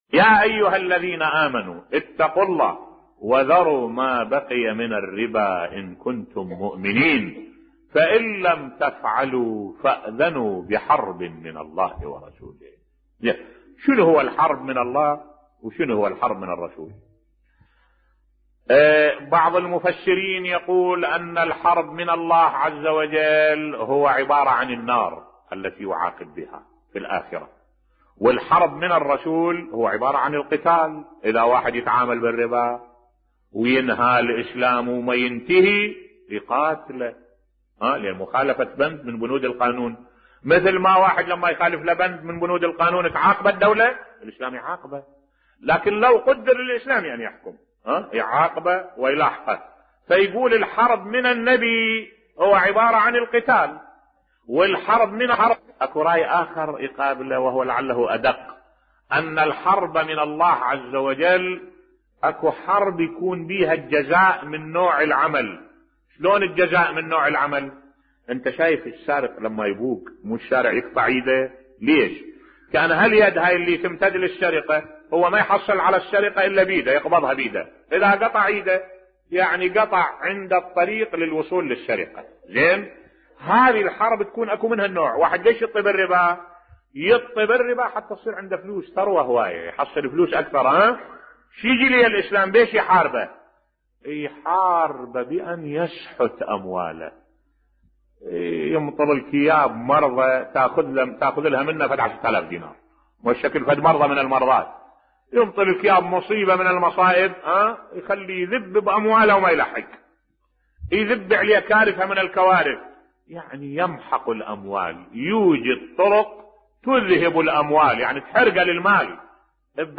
ملف صوتی رأيت بنفسي عاقبة 20 شخصية تعاملت بالربا بصوت الشيخ الدكتور أحمد الوائلي